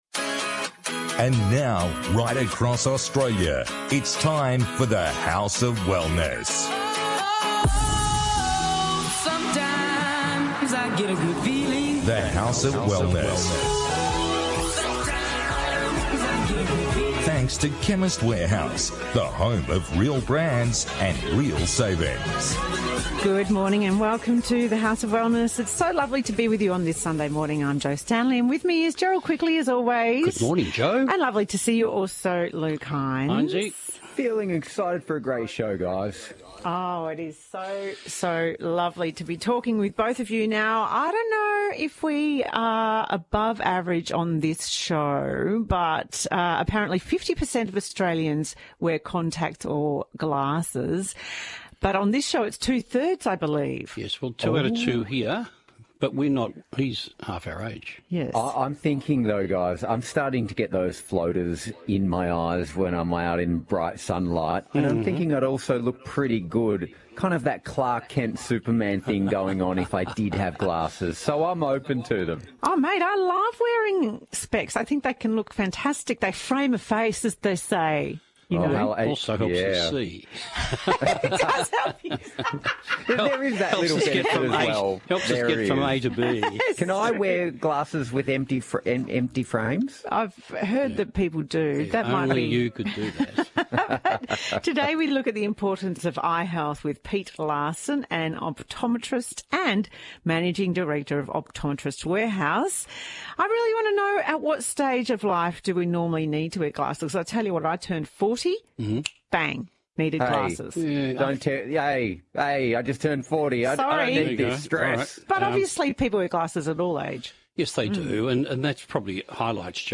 This week, the team discusses maintaining optimal eye health at friendly rates, varicose veins and more.